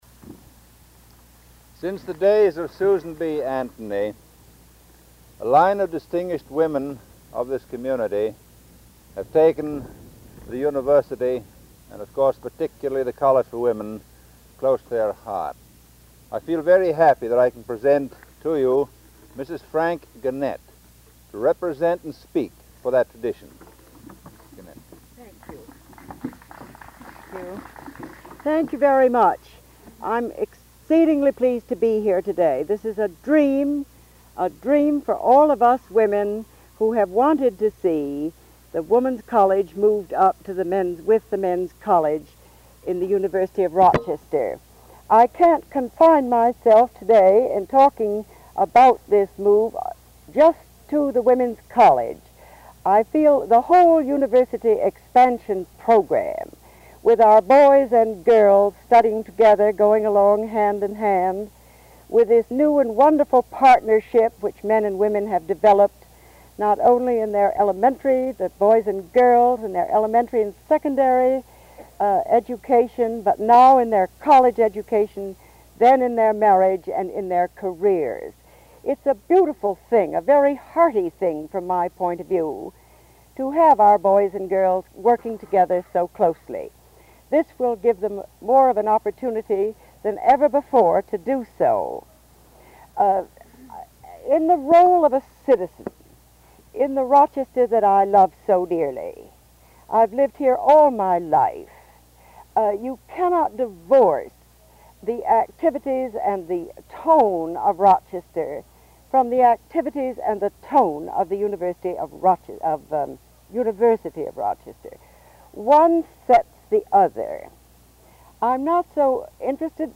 1952 Groundbreaking for Susan B. Anthony Hall and Spurrier Gymnasium
Remarks